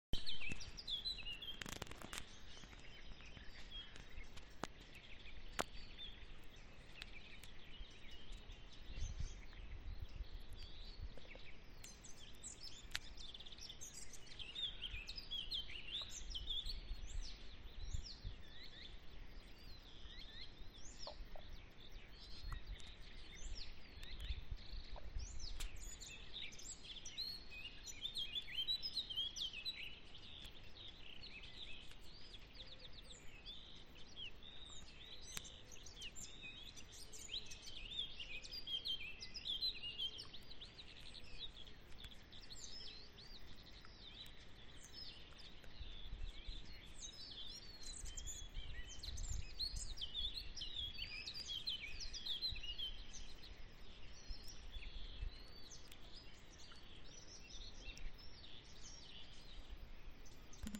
Birds -> Warblers ->
Blackcap, Sylvia atricapilla
StatusSinging male in breeding season